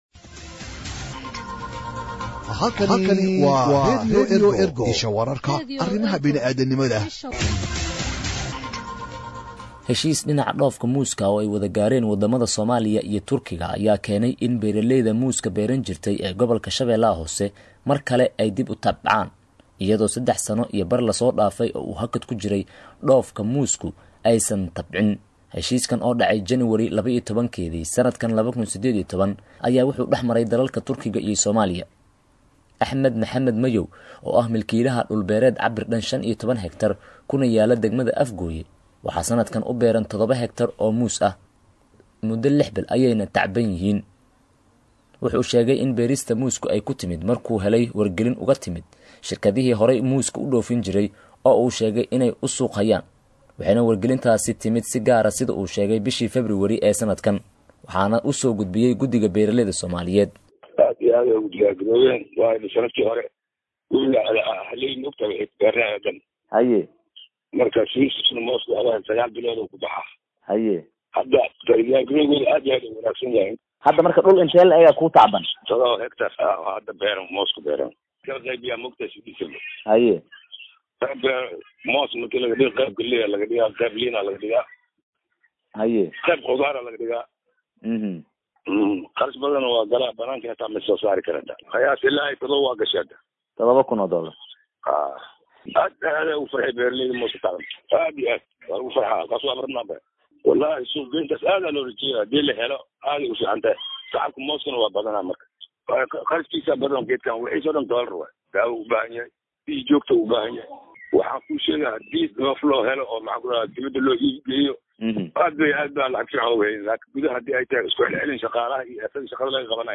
Warbixin-Dhoofka-Muuska-Soomaalya-.mp3